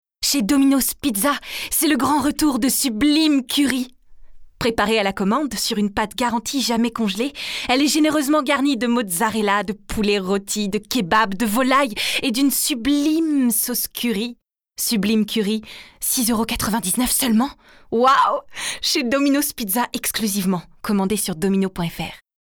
EXTRAITS VOIX
PUBLICITES /SLOGAN /BANDE ANNONCE